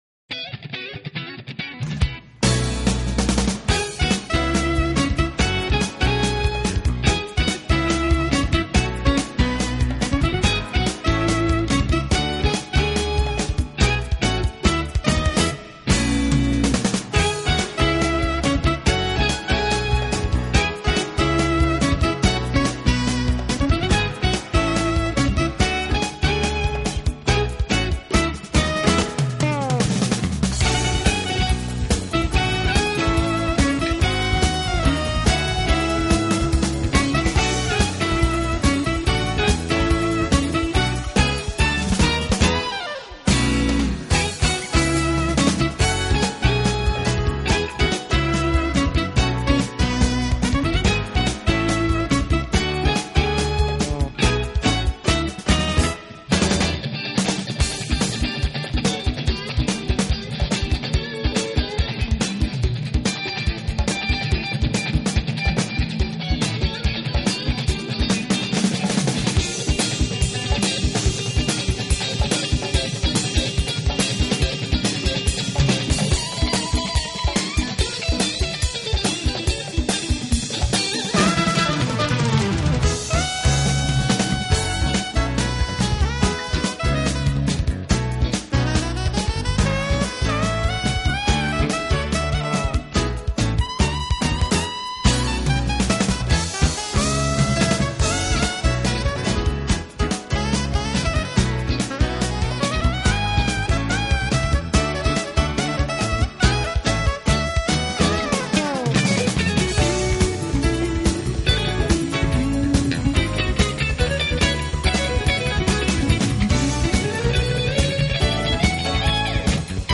guitar
tenor & soprano sax, flute
Electric bass
drums
keyboards
percussion
piano
acoustic bass